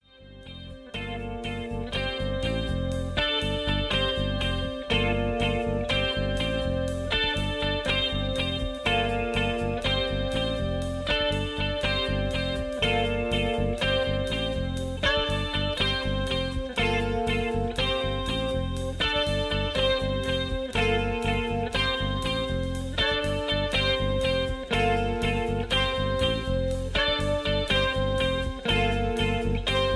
Tags: studio tracks , sound tracks , backing tracks , rock